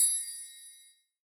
DDW Perc 3.wav